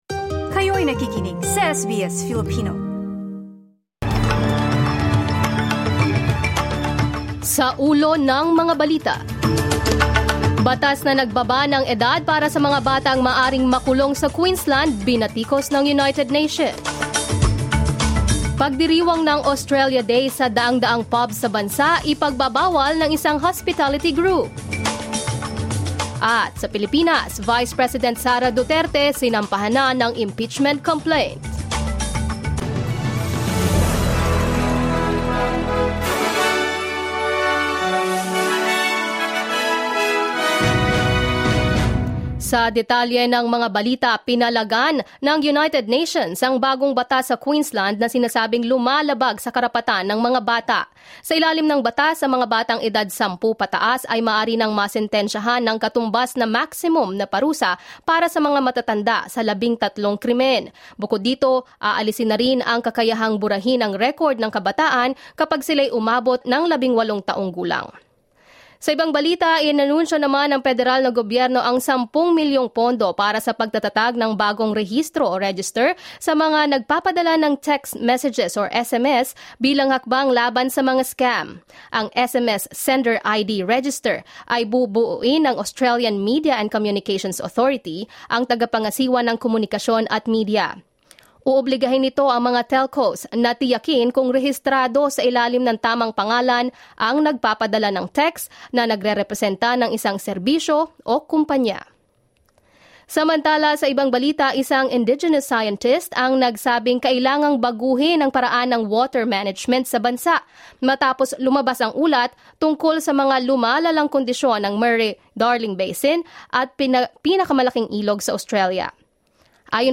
SBS News in Filipino, Tuesday 3 December 2024